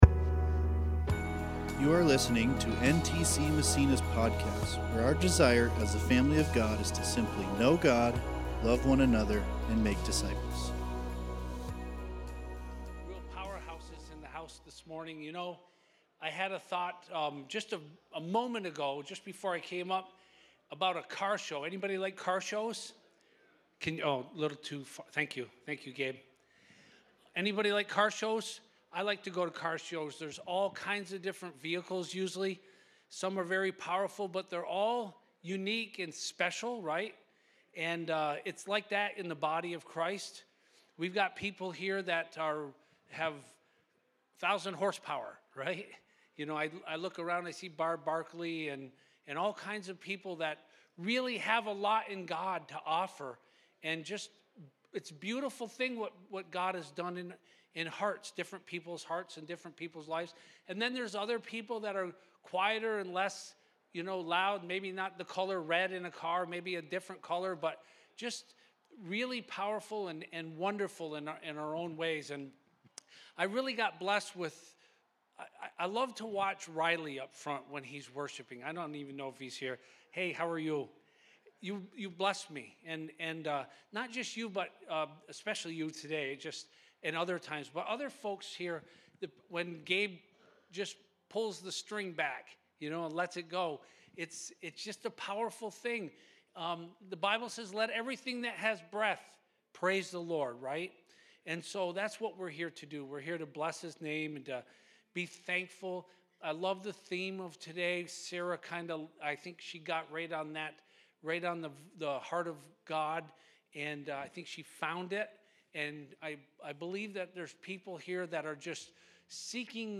This Sunday at NTC Massena, we continue in week 9 of our growing series!